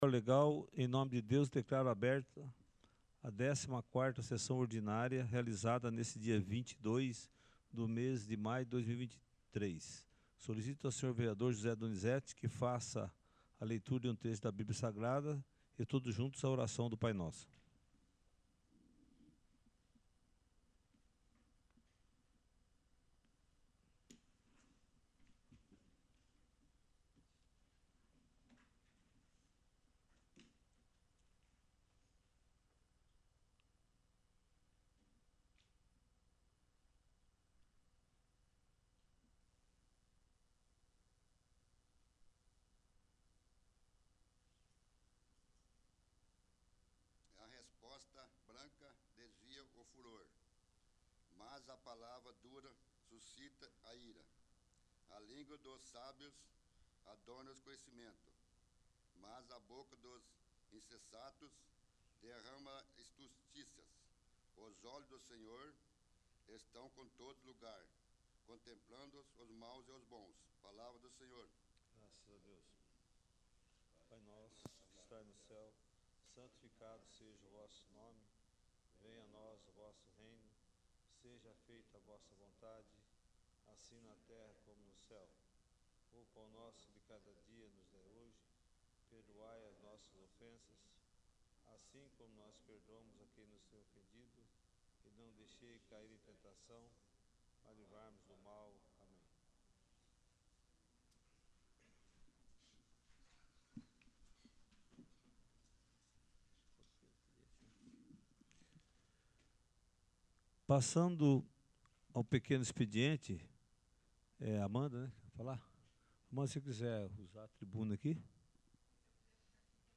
14º. Sessão Ordinária